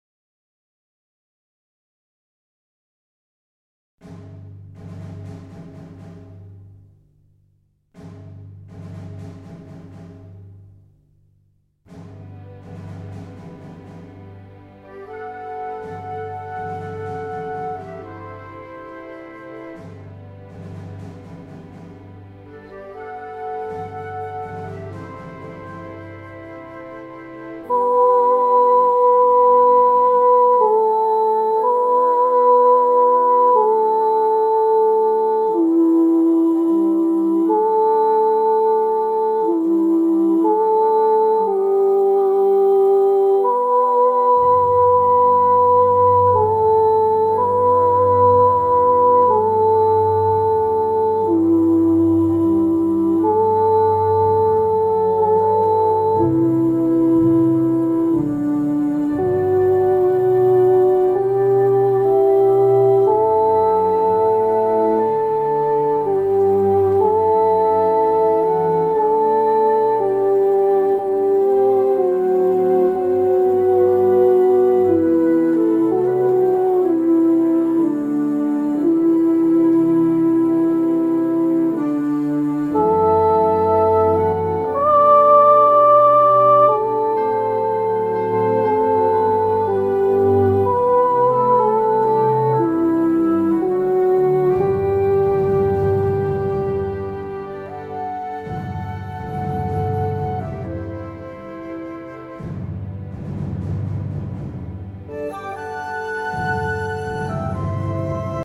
Lo ULTIMO sopranos
Hymn-to-the-fallen-SOP1-1mitad.mp3